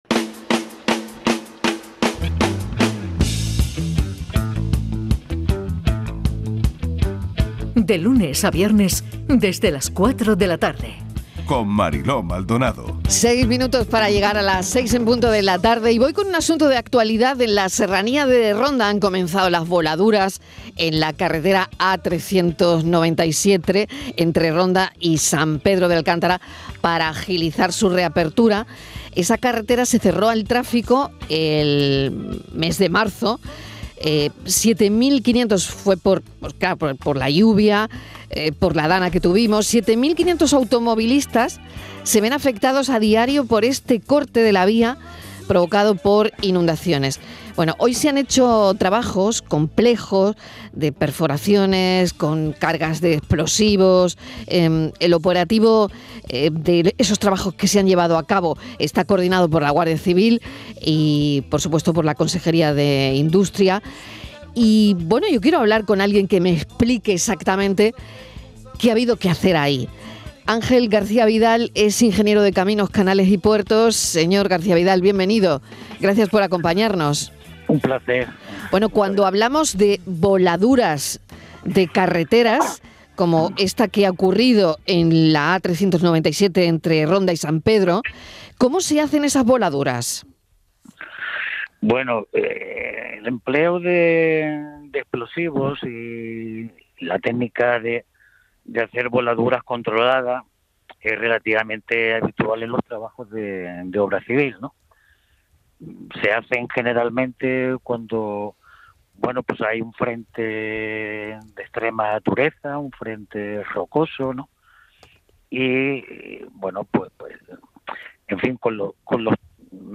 ENTREVISTA EN DIRECTO EN CANAL SUR RADIO